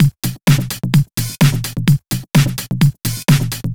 VMH1 Minimal Beats 02.wav